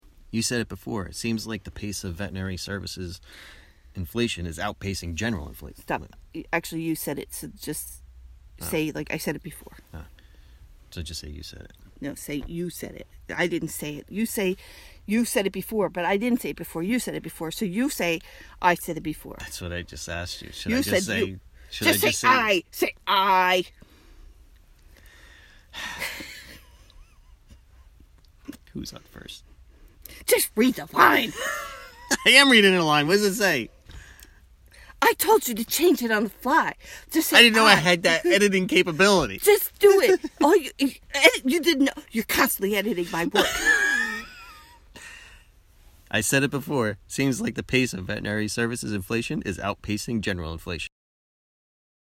March 31, 2018 This weekend, we celebrate the 1 year anniversary of GFNJ's podcast, About Greyhounds. Here's a quick outtake from an episode recorded this evening.
And note that we're totally playing with each other, contrary to how it sounds.